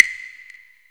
SWPERC.wav